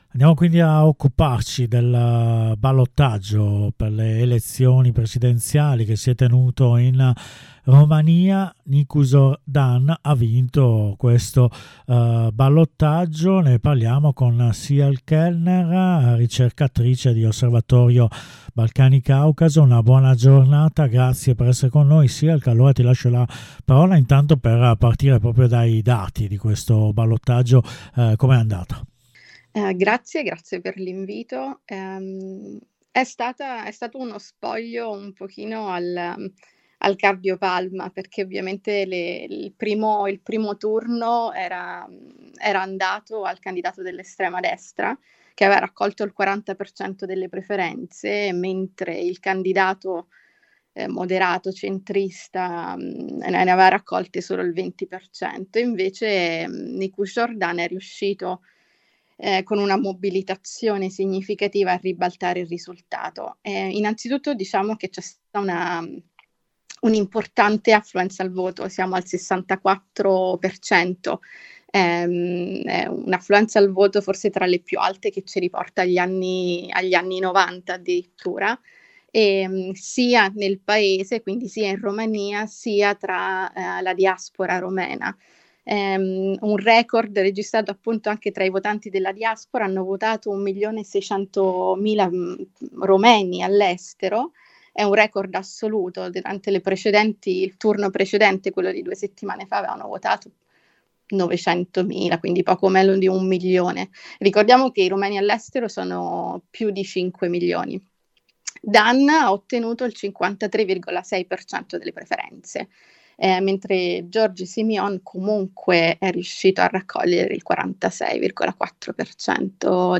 ai microfoni di Radio Onda d’Urto